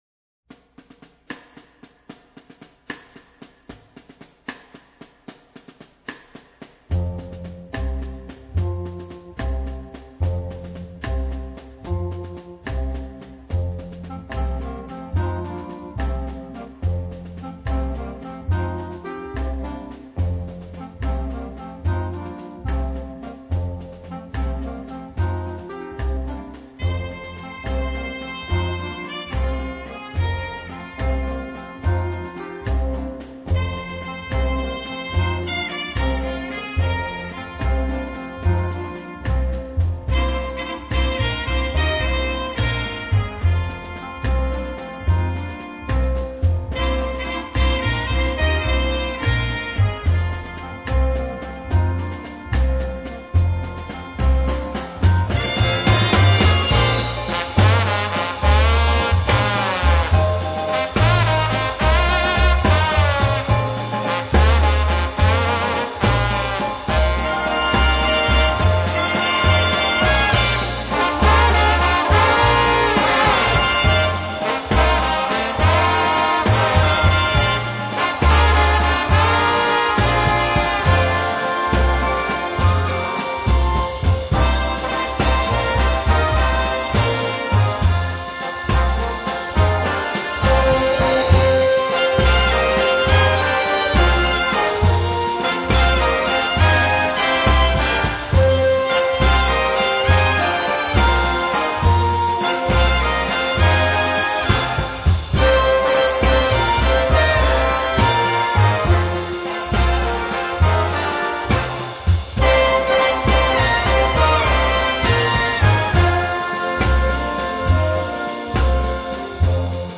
a swankier tune